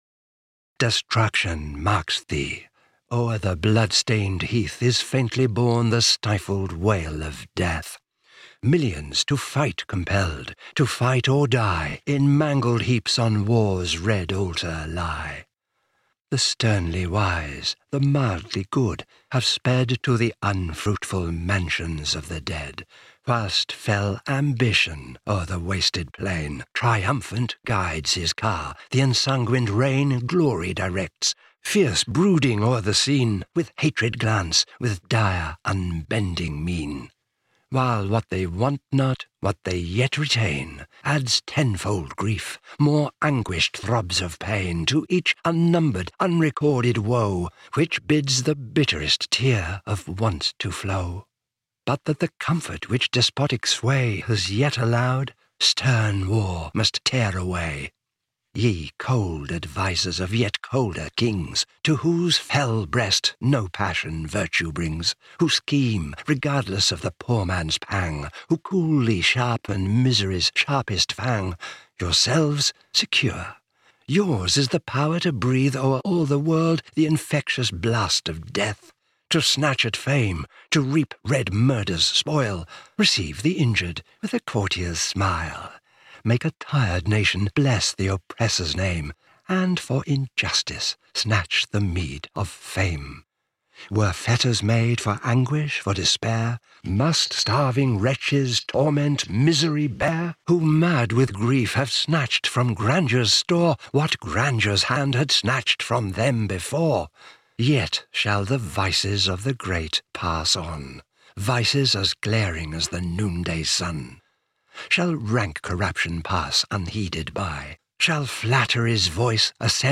This is my tribute read to a young peacenik wide awake to his world and asking, as we are still... WHY WAR?